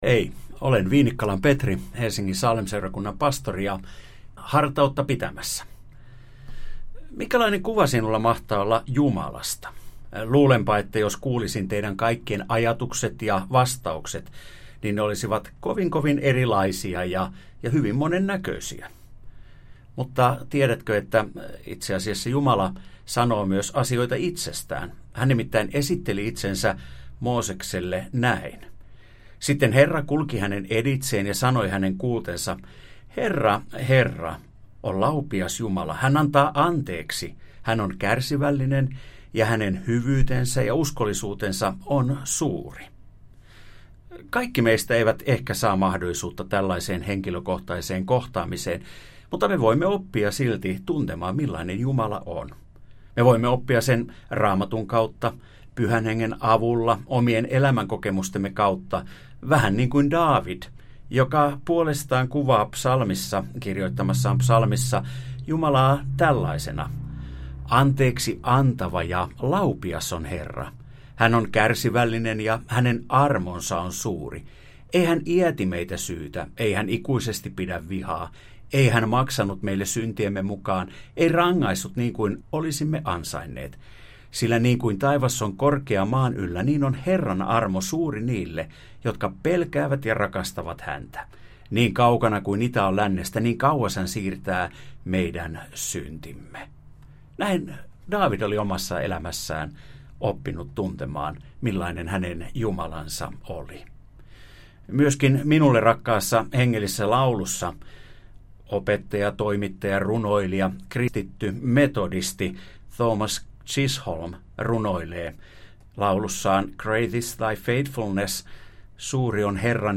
KUUNTELE: Tammikuun 2021 radiohartaudet täältä
Radio Dei lähettää FM-taajuuksillaan radiohartauden joka arkiaamu kello 7.50. Radiohartaus kuullaan uusintana iltapäivällä kello 17.05. Radio Dein radiohartauksien pitäjinä kuullaan laajaa kirjoa kirkon työntekijöitä sekä maallikoita, jotka tuntevat radioilmaisun omakseen. Pääpaino on luterilaisessa kirkossa, mutta myös muita maamme kristillisen perinteen edustajia kuullaan hartauspuhujina.